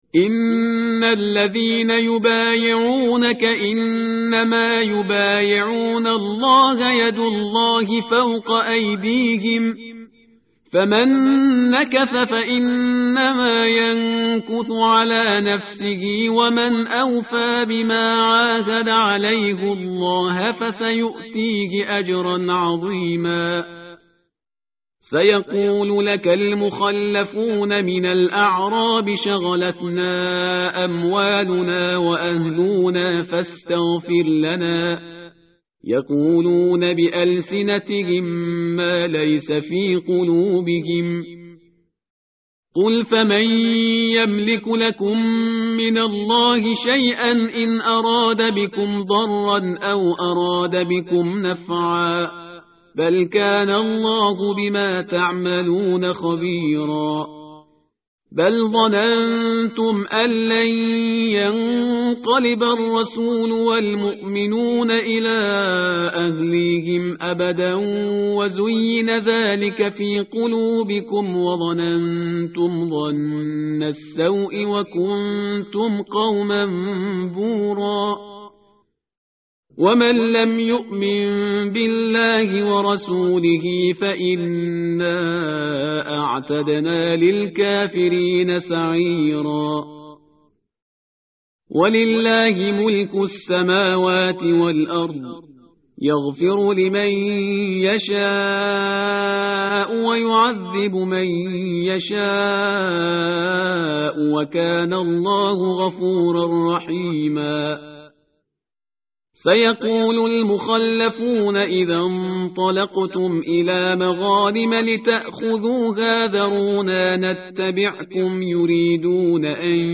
متن قرآن همراه باتلاوت قرآن و ترجمه
tartil_parhizgar_page_512.mp3